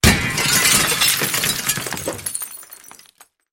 На этой странице собраны звуки, связанные с зеркалами: от едва уловимых отражений до резких ударов.
Звук разбитого зеркала